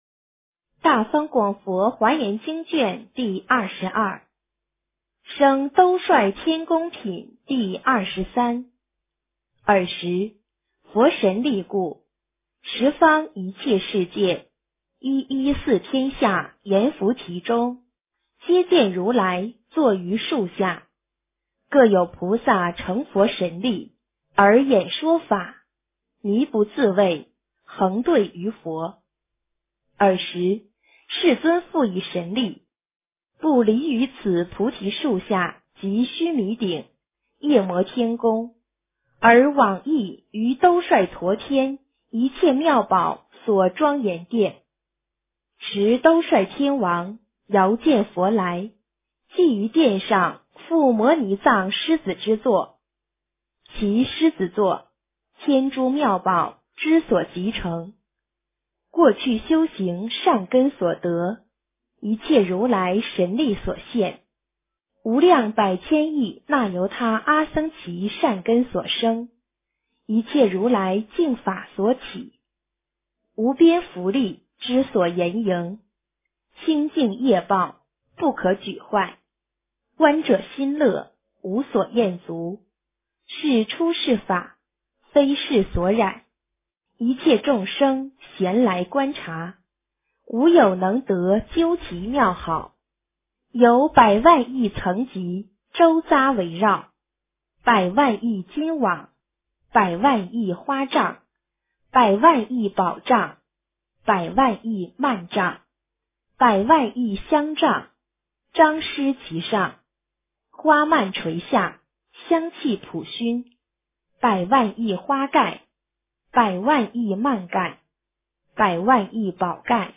标签: 佛音 诵经 佛教音乐